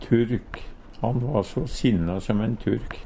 turk - Numedalsmål (en-US)